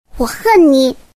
萝莉音我恨你音效_人物音效音效配乐_免费素材下载_提案神器